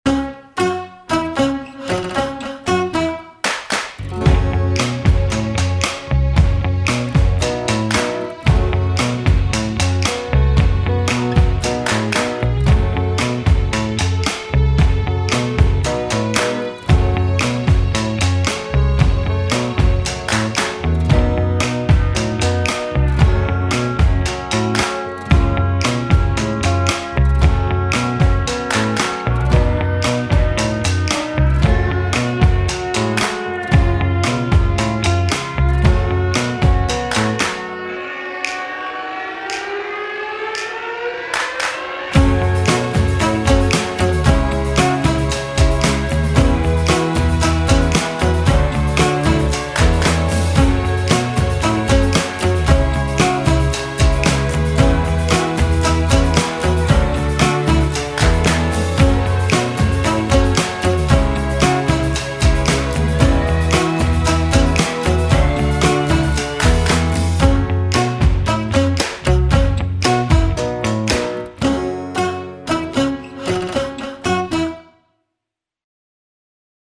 Beltoon